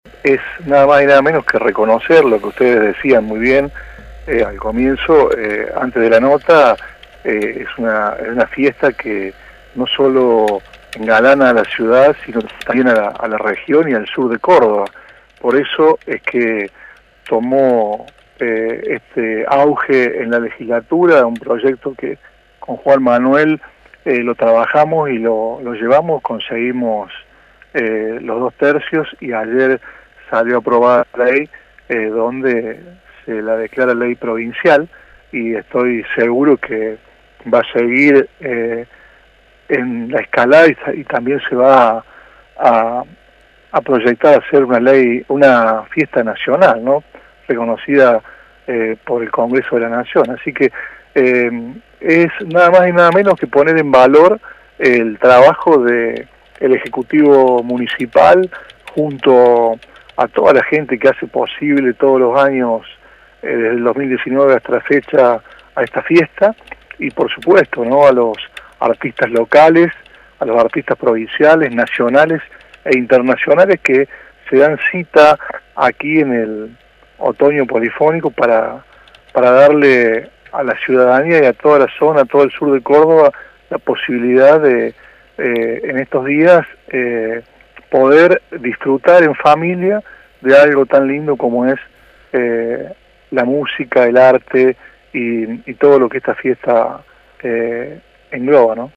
El legislador Ariel Grich, de la UCR, dijo que el objetivo es reconocer el evento.